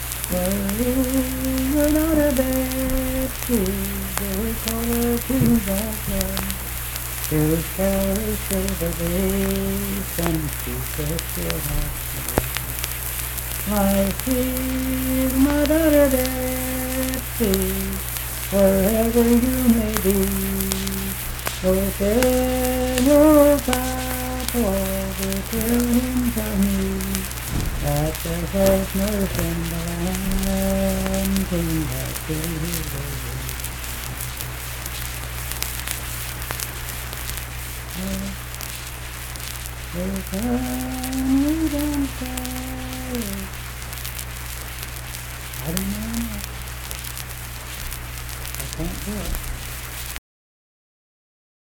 Unaccompanied vocal music performance
Verse-refrain 2(4-6).
Voice (sung)